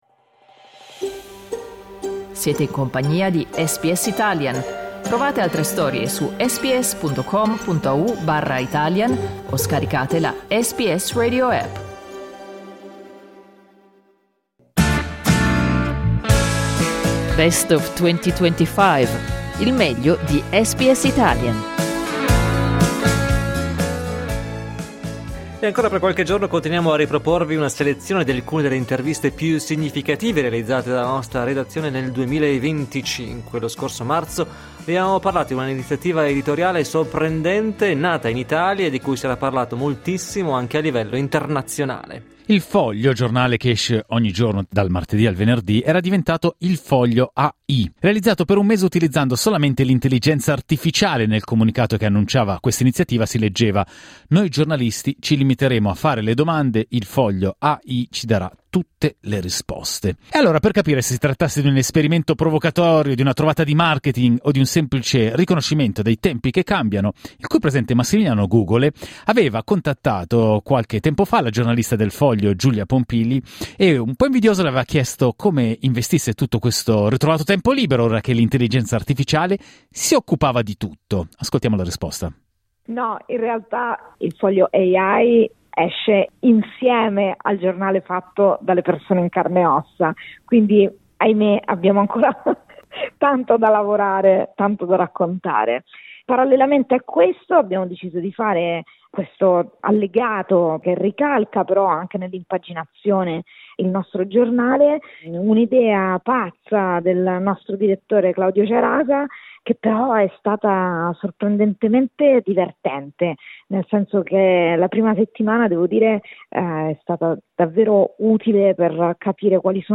conversazione